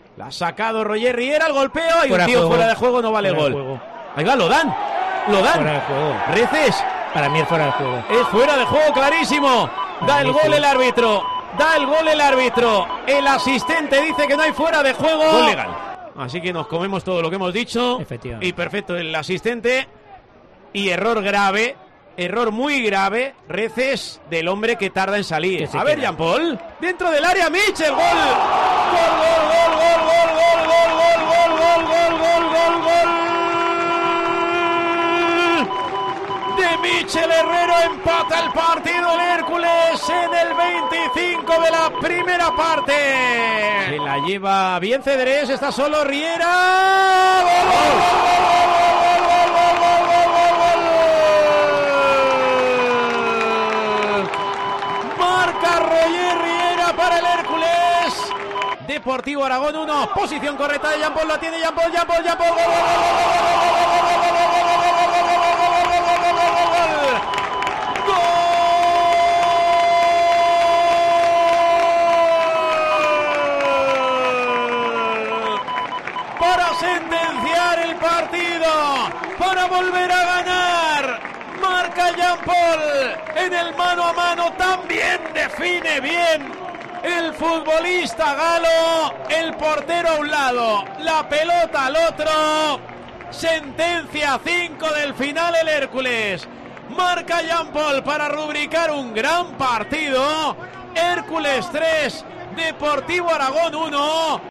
Escucha los goles del Hércules 3-1 Dep. Aragón
Así vivimos la primera victoria con Lolo Escobar en el banquillo en Tiempo de Juego Alicante.